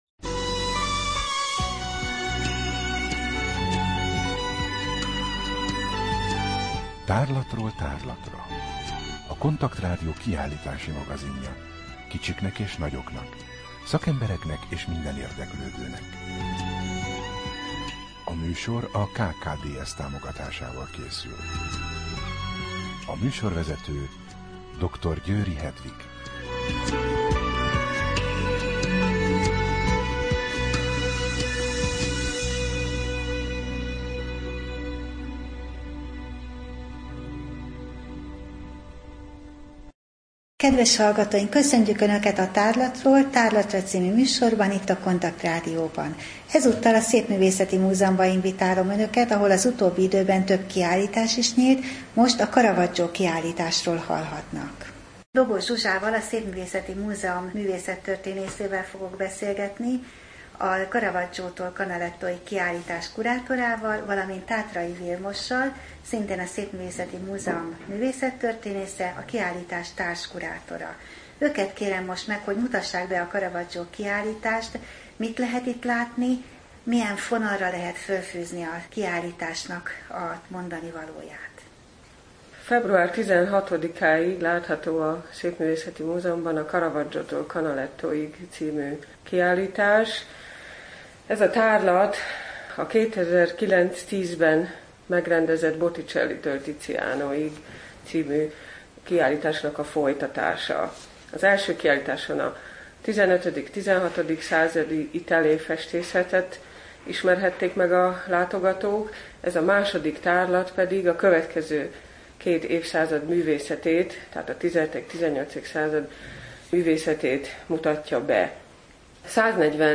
Rádió: Tárlatról tárlatra Adás dátuma: 2013, December 30 Tárlatról tárlatra / KONTAKT Rádió (87,6 MHz) 2013. december 30.